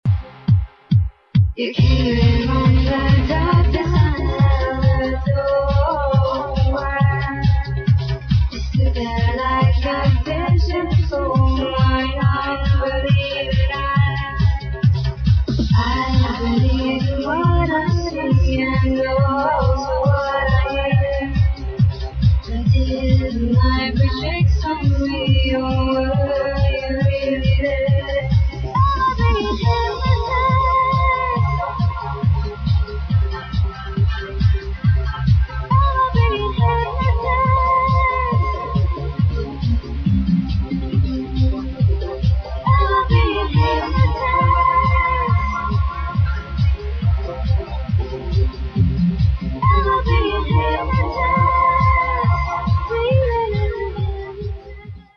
[ TRANCE / TECHNO / DOWNTEMPO ]